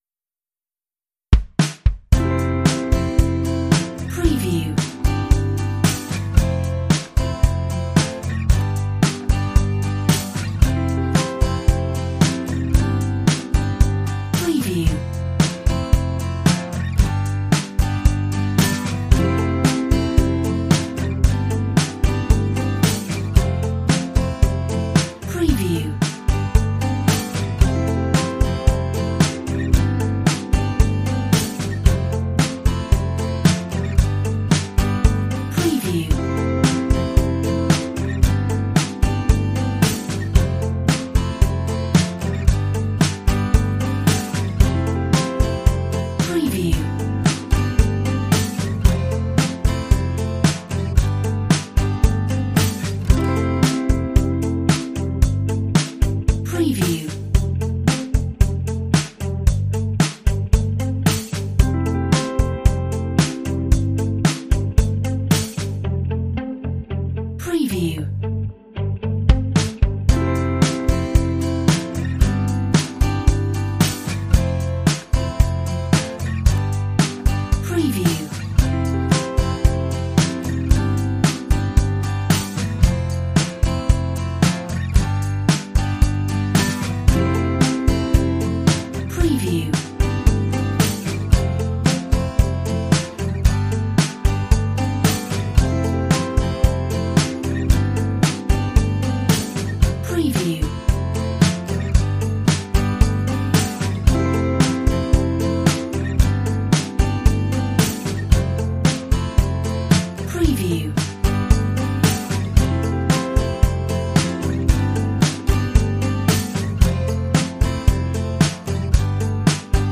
Relaxing acoustic